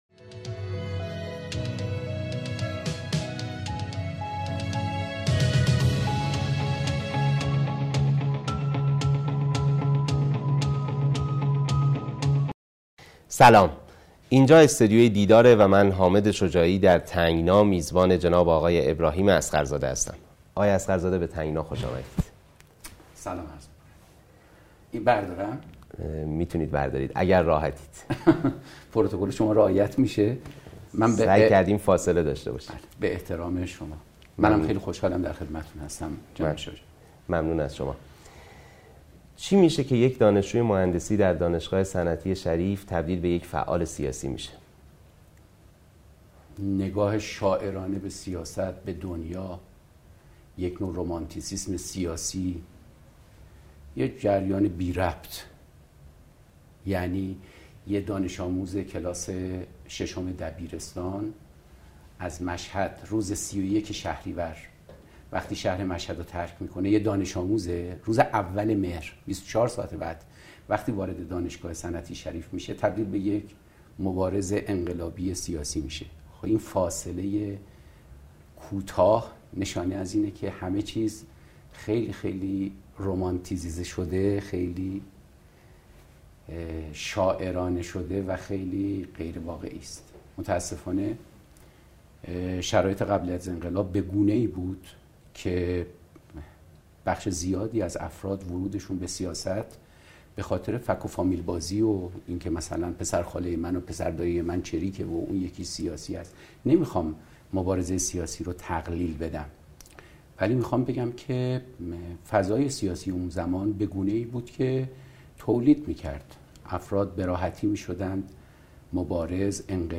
نسخه صوتی گفتگو با ابراهیم اصغرزاده؛ از دیوار سفارت تا دیوار اوین
«تنگنا» عنوان برنامه‌ای از مجموعه رسانه‌ای دیدار است که به گفتگوهای صریح با سیاستمداران ایرانی در مورد زندگی سیاسی آن‌ها اختصاص دارد. ابراهیم اصغرزاده میهمان دومین برنامه تنگنا بوده است.